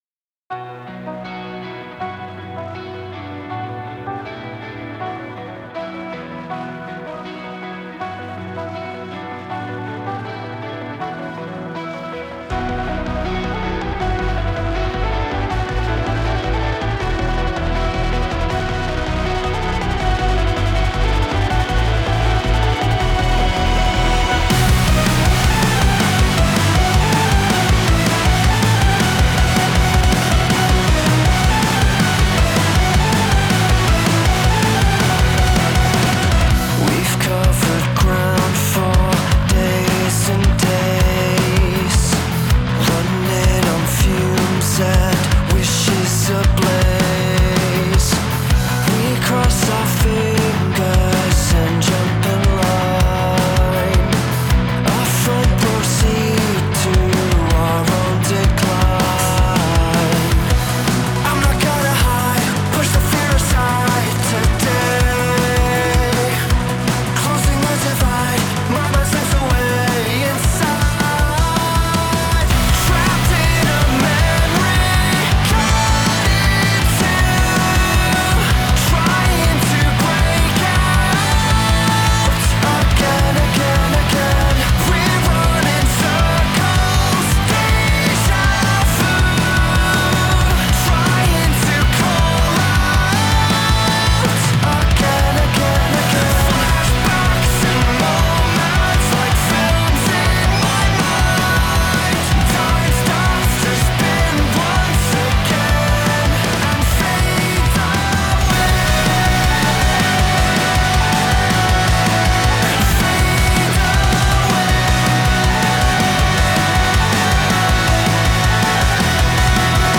a song that sounds like a 2000s cartoon theme